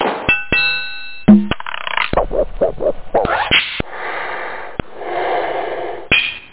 drum_kits
Amiga 8-bit Sampled Voice
1 channel